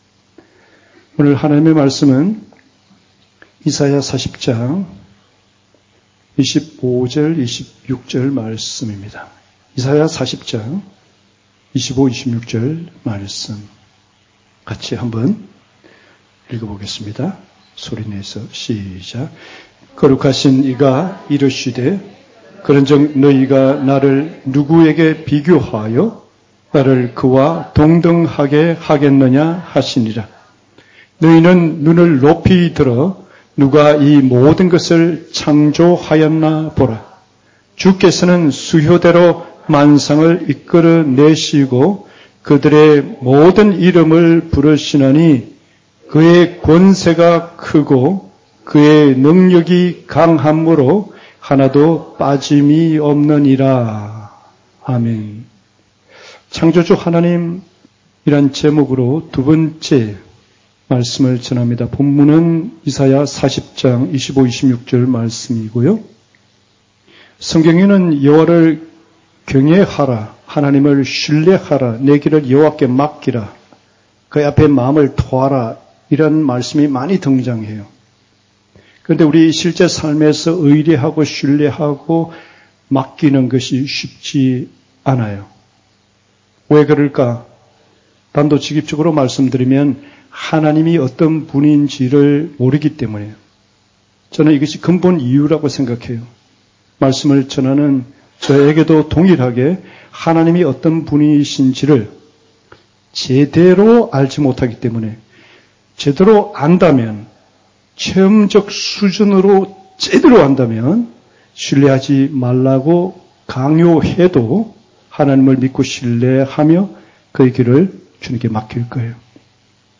독립된 설교 (주일) - 240929_하나님의말씀_지혜_약1_5